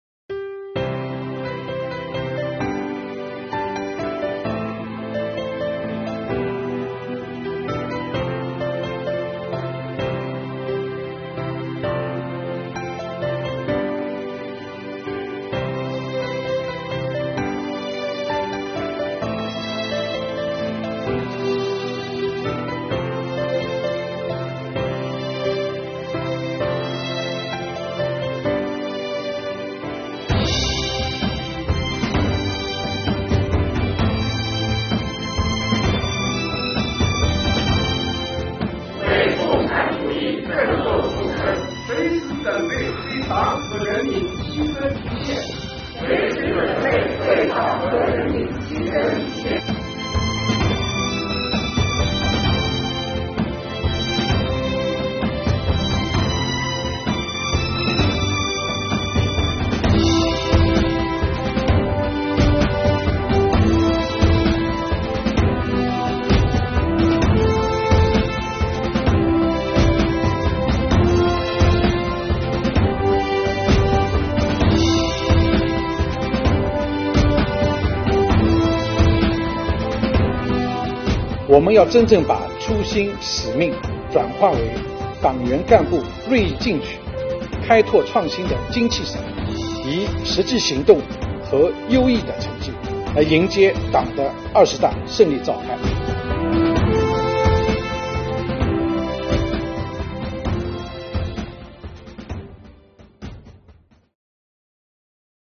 为庆祝中国共产党成立101周年，进一步推动党史学习教育常态化长效化，持续深化“党课开讲啦”活动，引领税务干部弘扬伟大建党精神，7月20日下午，崇明区税务局开展党委理论学习中心组（扩大）学习暨“红色光影映初心 信仰如山聚力行——2022年我的电影党课”主题活动。
唱响红色歌曲
党员、干部挥动手中党旗，以昂扬斗志和饱满热情，齐声高唱《没有共产党就没有新中国》，在革命歌曲和激昂旋律中，亮初心、颂党恩、扬斗志。